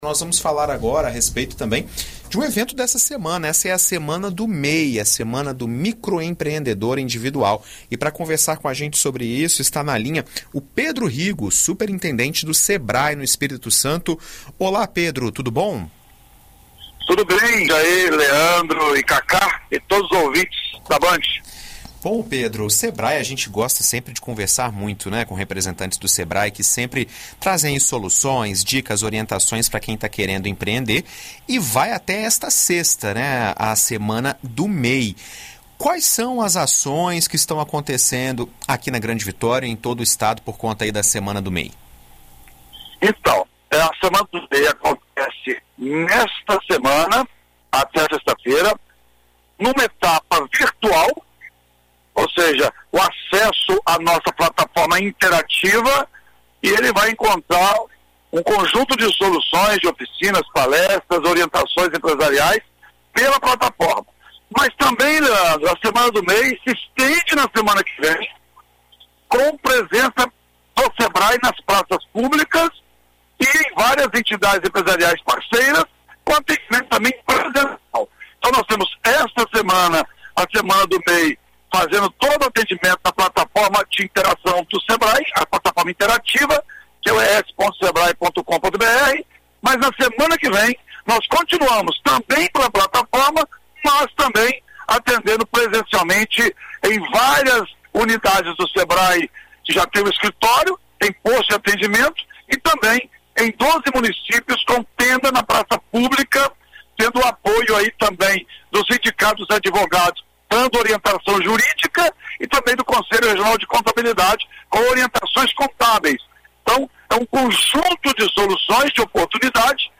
Em entrevista à BandNews FM Espírito Santo nesta quarta-feira (18)